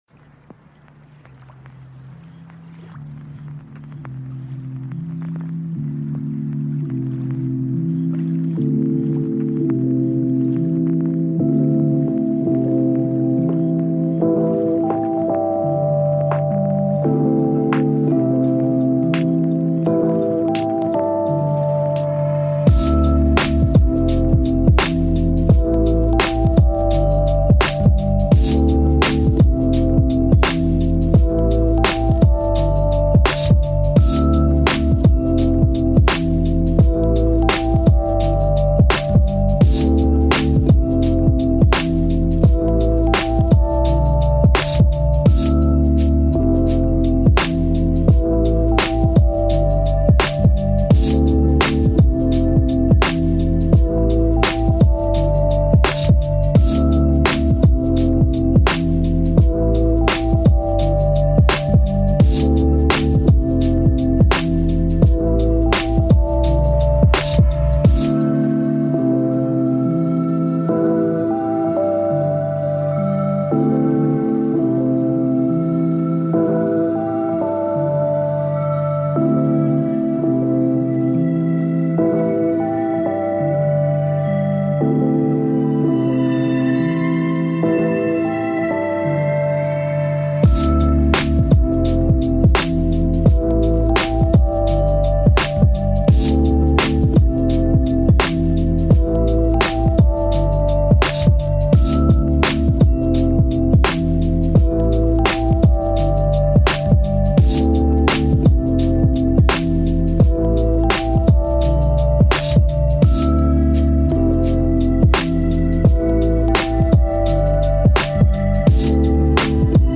Pluie Nocturne : Ambiance Seule